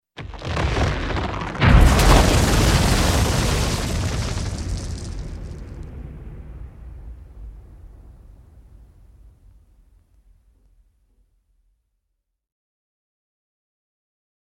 Звук трескающейся Земли